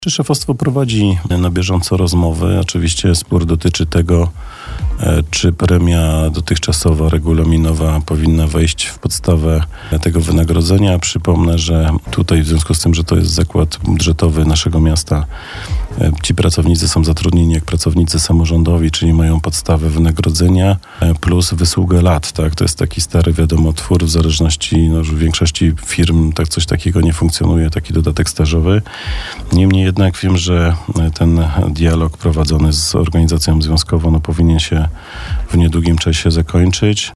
To jest stary twór, w większości firm coś takiego nie funkcjonuje […] objaśniał na naszej antenie wiceprezydent Przemysław Kamiński.